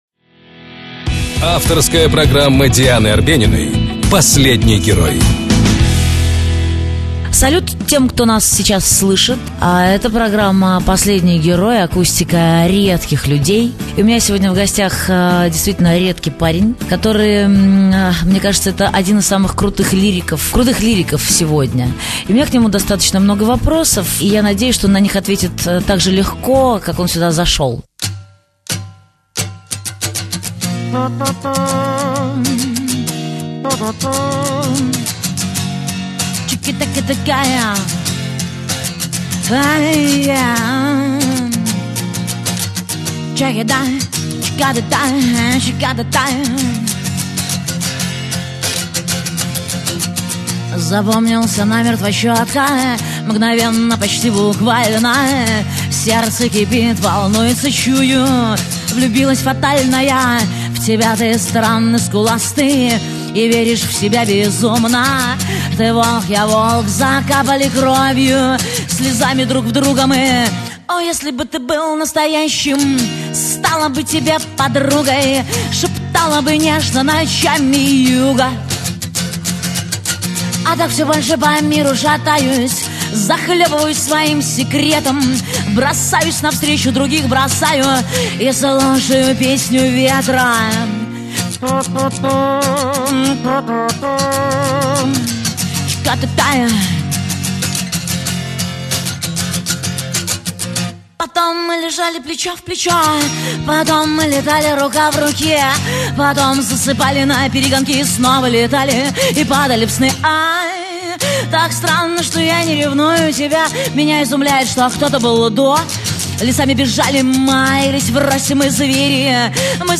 30 ноября гостем программы стал российский музыкант и актер, лидер группы Uma2rmaH Владимир Кристовский.